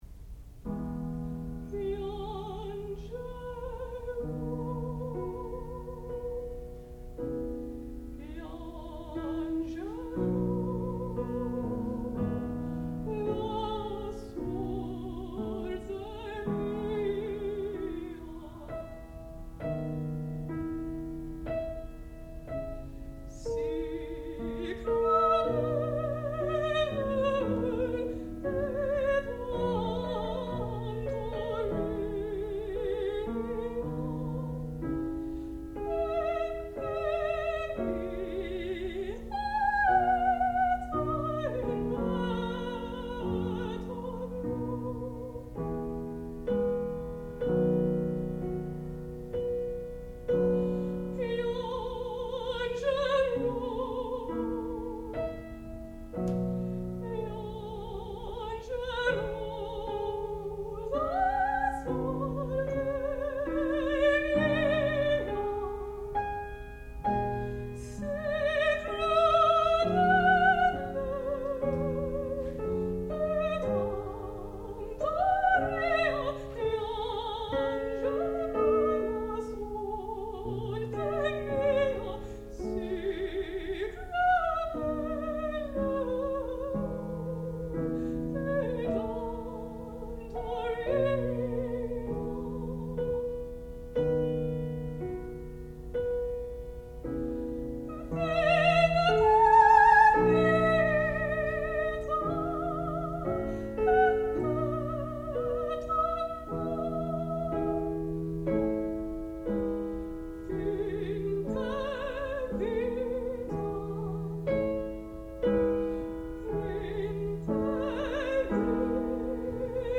sound recording-musical
classical music
Qualifying Recital